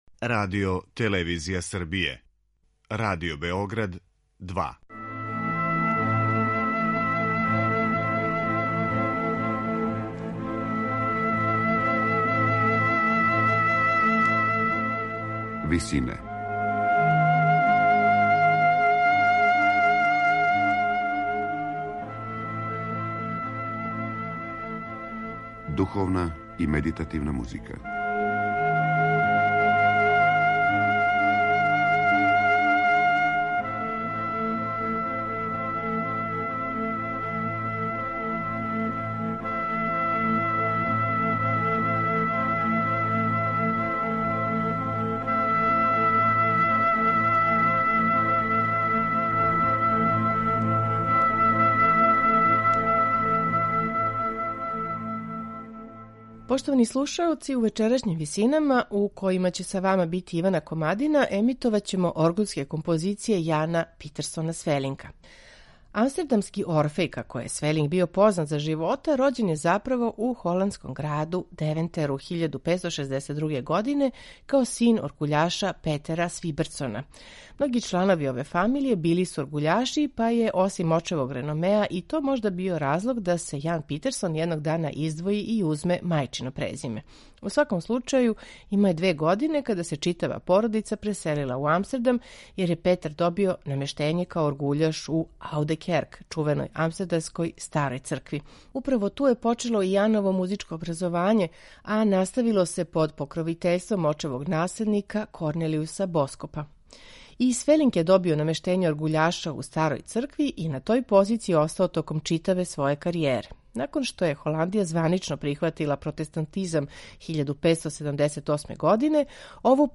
У вечерашњим Висинама слушаћемо Свелинкове оргуљске токате, обраде псалама, фантазије и корале